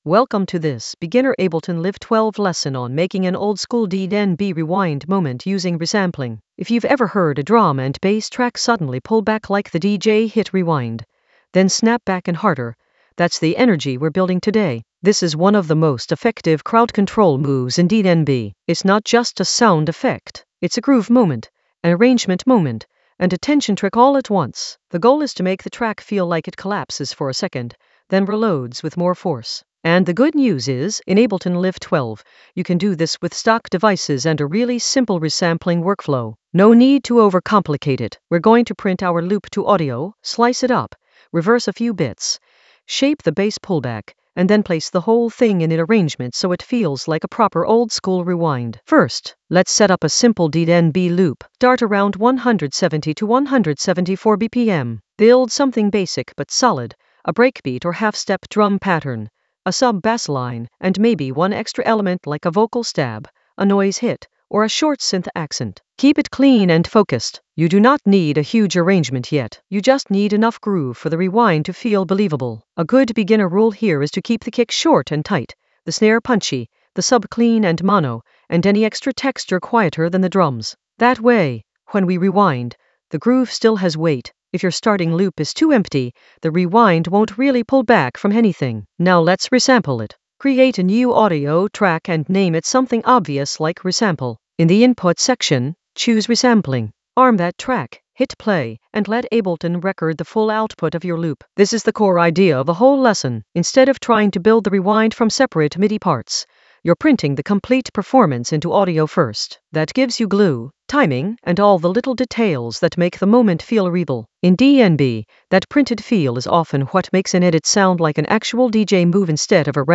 An AI-generated beginner Ableton lesson focused on Compose oldskool DnB rewind moment using resampling workflows in Ableton Live 12 in the Groove area of drum and bass production.
Narrated lesson audio
The voice track includes the tutorial plus extra teacher commentary.